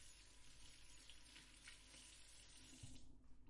电影的声音 " 浇灌的水
描述：用Zoom H2记录，水倒入铸铁锅中。
标签： 厨房
声道立体声